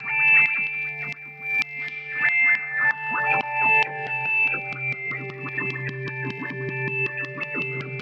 Tag: 120 bpm Electronic Loops Synth Loops 1.35 MB wav Key : Unknown